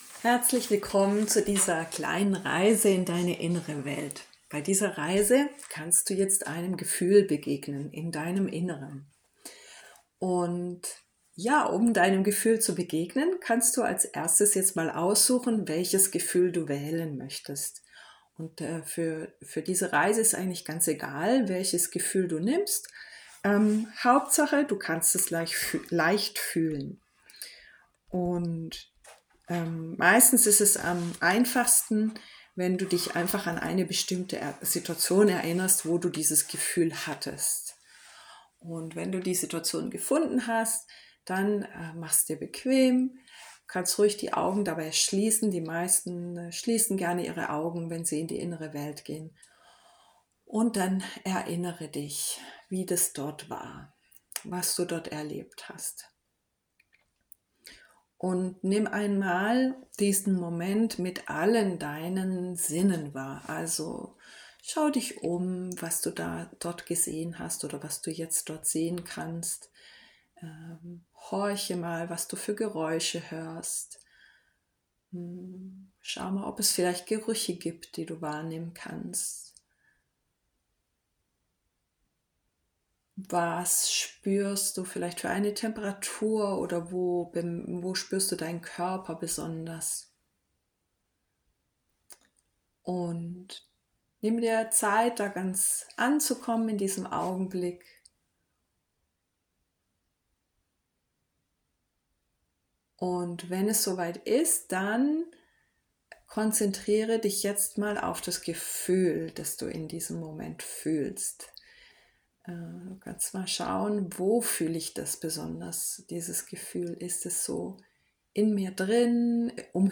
Geführte Reise zu deinen Emotionen